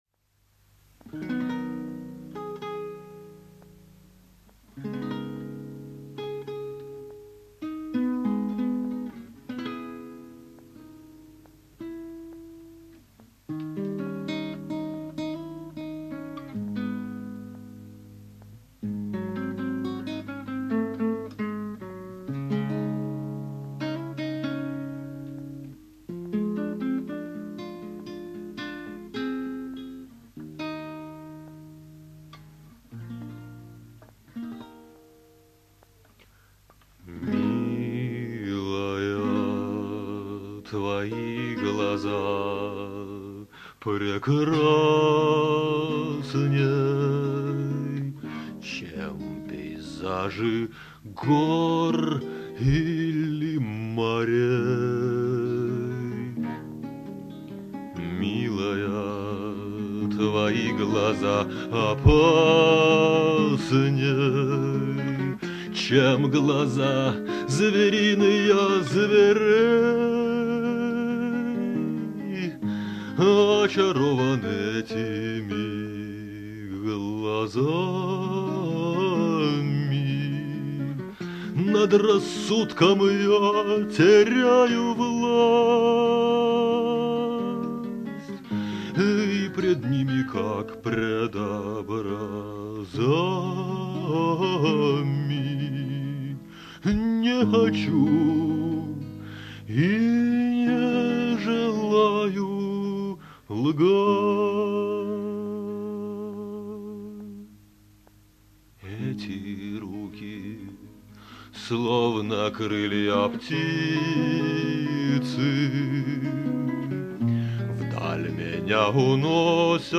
песни 1992-97 гг. в исполнении автора.
ХХ века в собственной мастерской (правда – скульптурной).
дённых в формат mp3, 128kbps, 44kHz, stereo: